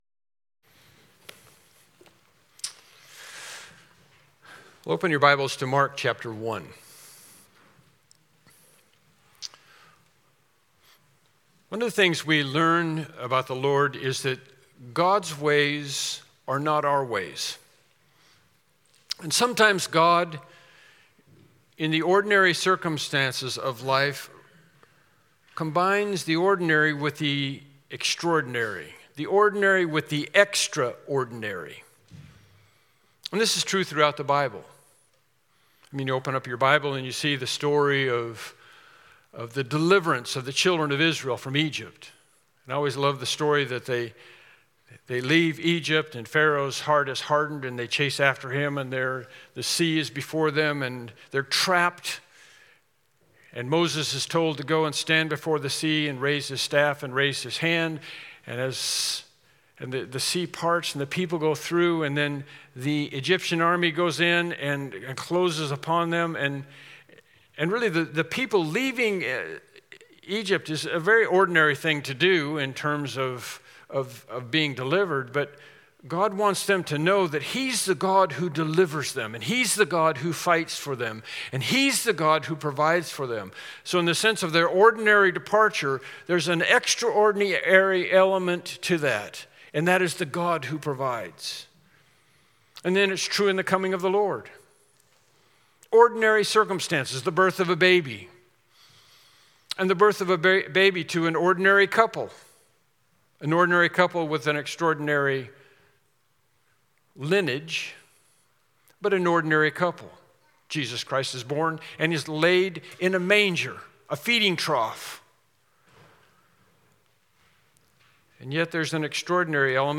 Mark 1:1-8 Service Type: Morning Worship Service « Standing in Christ for Christ Lesson 6